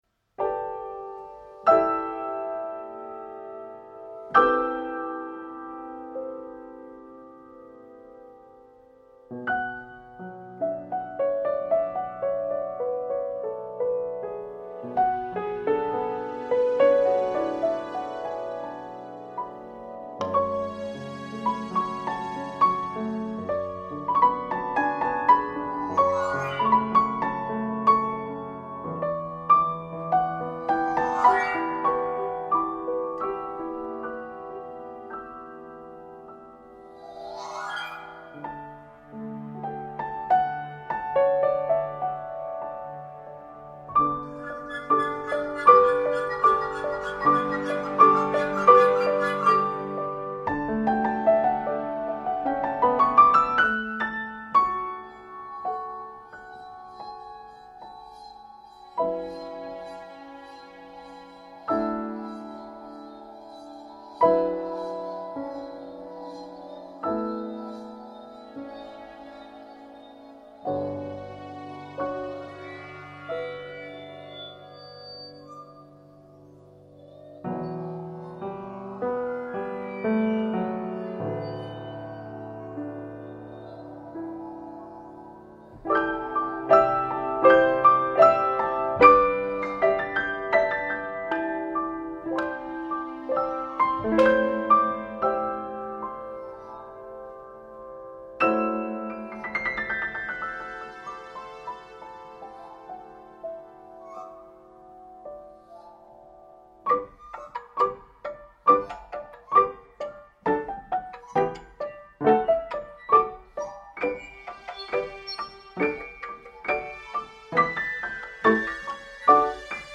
coro di bambini